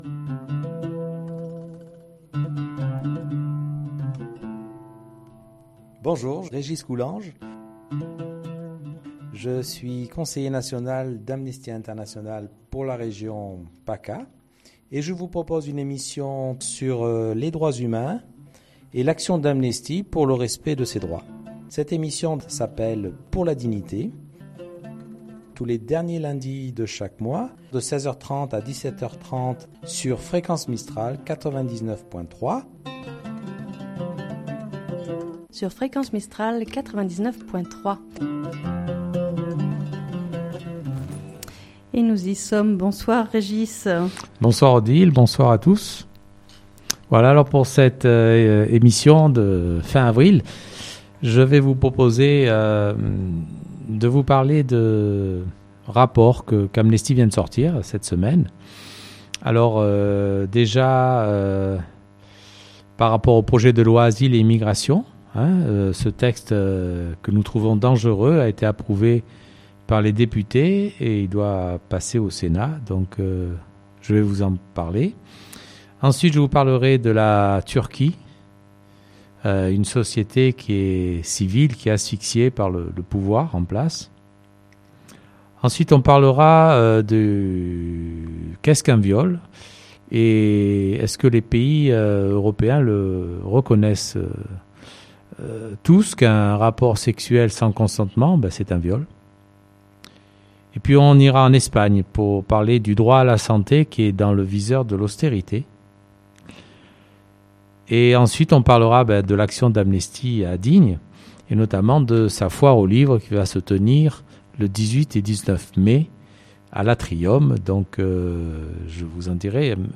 en direct sur votre radio Fréquence mistral Digne 99.3